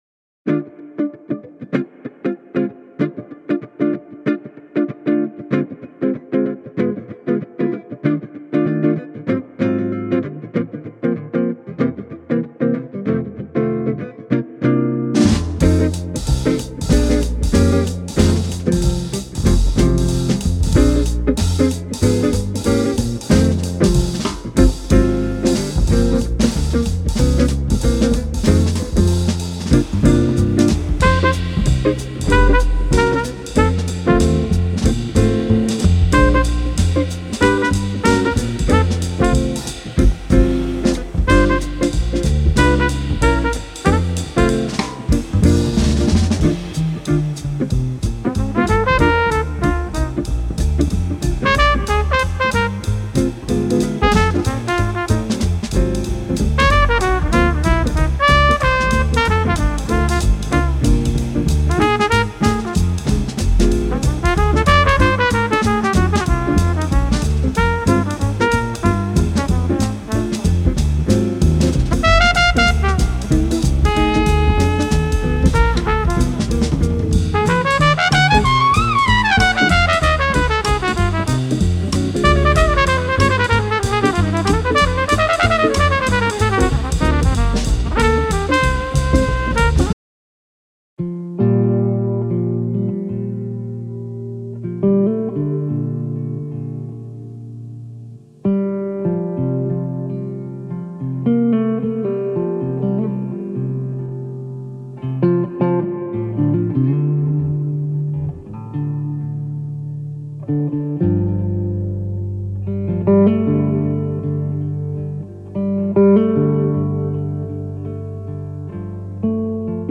Orgue
Batterie